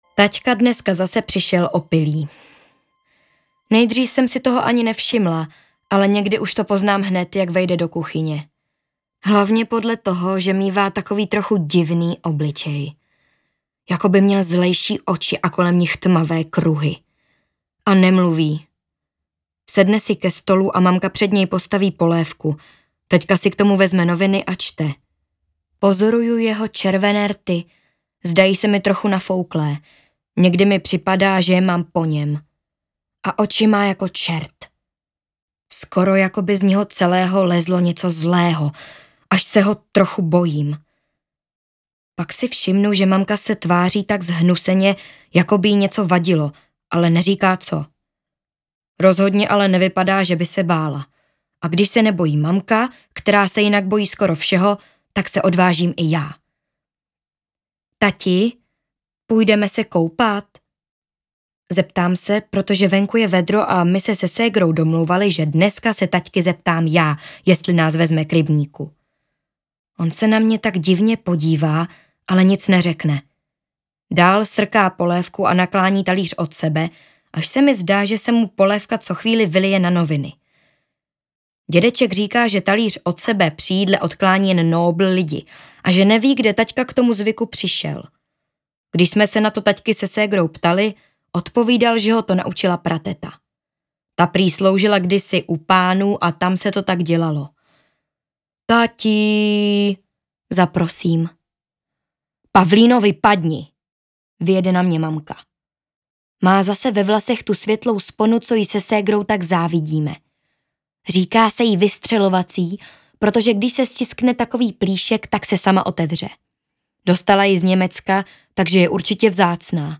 Délka: 14 h 1 min Interpret: Miroslav Hanuš Vydavatel: OneHotBook Vydáno: 2023 Jazyk: český Typ souboru: MP3 Velikost: 791 MB